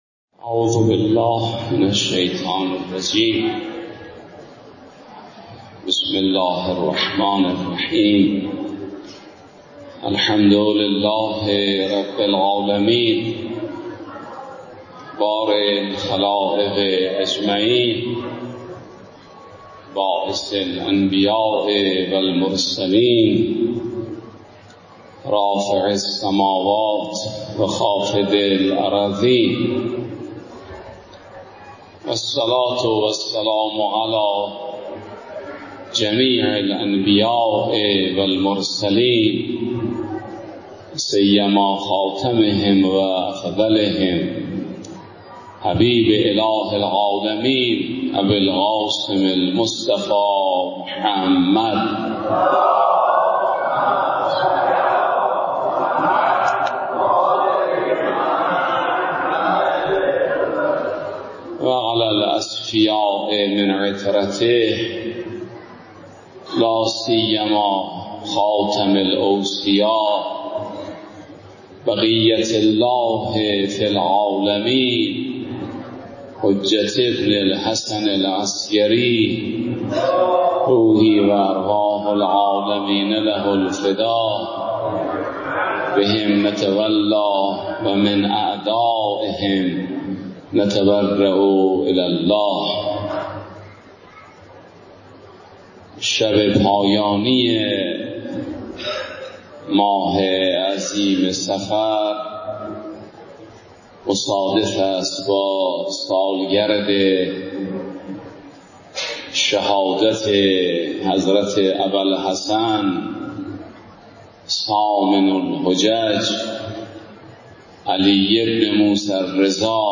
بیانات
در مراسم دهه آخر صفر (جلسه پنجم)
مراسم عزاداری ایام آخر ماه صفر